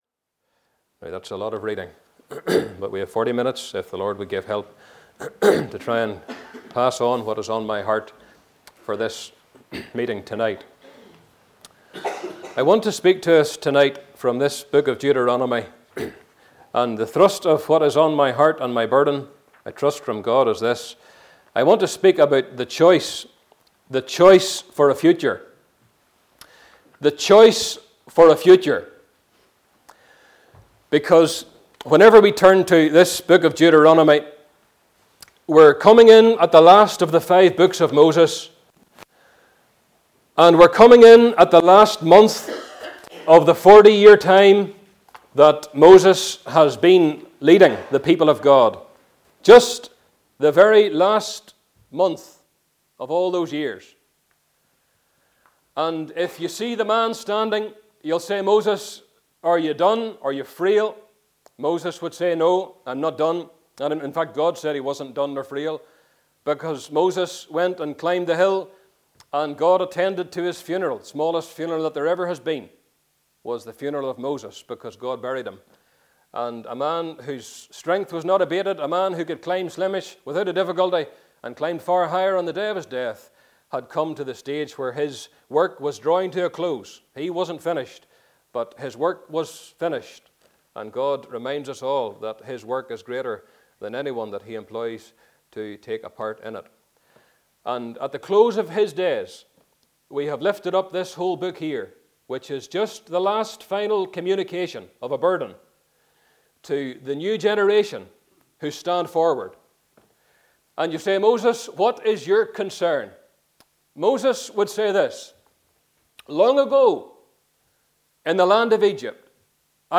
Passage: Deuteronomy Meeting Type: Ministry